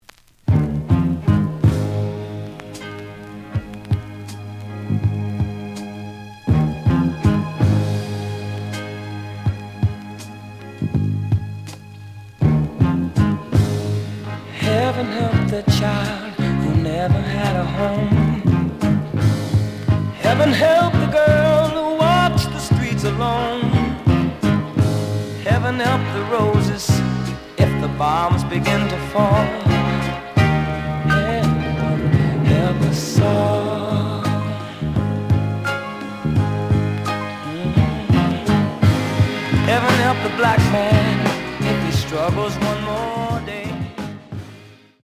●Genre: Soul, 70's Soul
Some click noise on beginning of B side due to scratches.